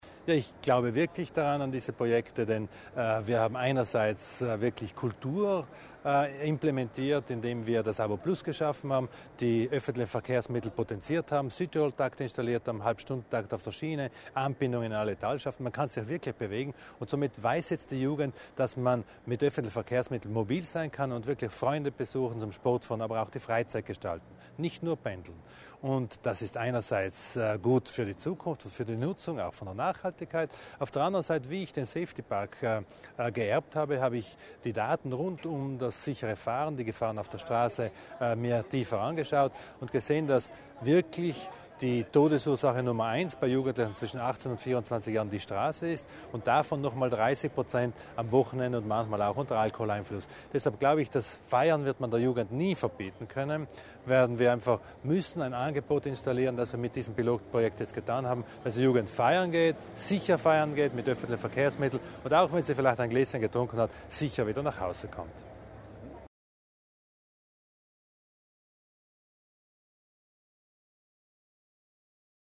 Landesrat Widmann über das Projekt Nightliner
(LPA) Die Mühlbacher Klause, die historische Schnittstelle zwischen dem Puster- und dem Eisacktal hat sich Mobilitätslandesrat Thomas Widmann heute (24. Juni) ausgesucht, um den neuen Nightliner-Dienst für die beiden Täler vorzustellen.